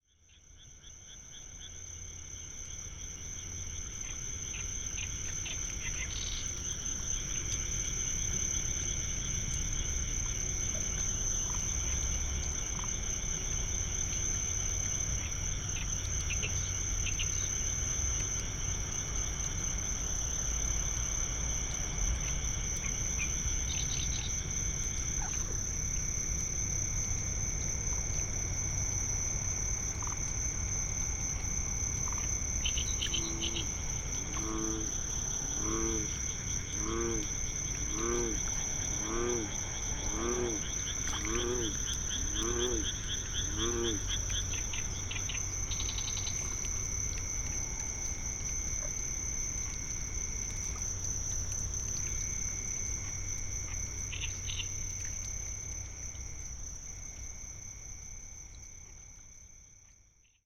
ZOOM H6, Telinga PRO-8 MK2, 2019年5月1日 新潟県
キビタキのさえずりです。
Telingaは直径57cmのパラボラディッシュと無指向性ステレオマイクによるパラボラマイクです。ターゲットにパラボラを向けると、目的の音は中央に定位し、周囲の音がステレオ集音できるという優れものです。